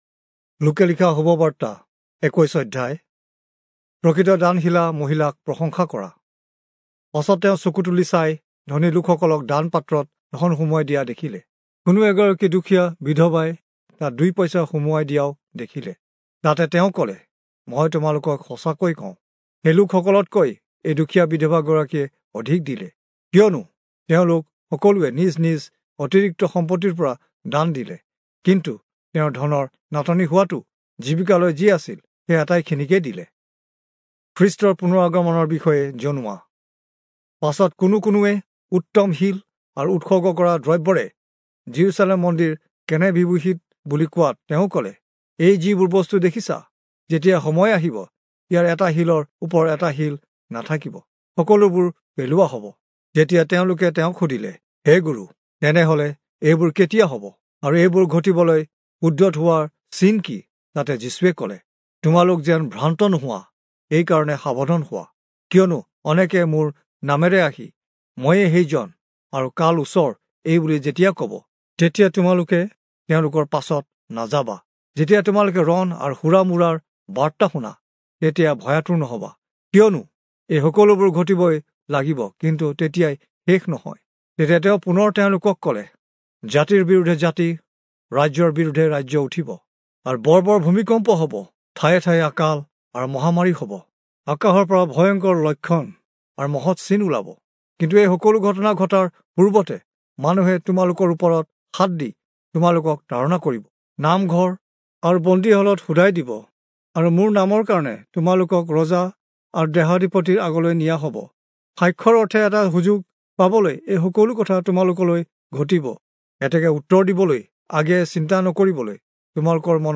Assamese Audio Bible - Luke 9 in Urv bible version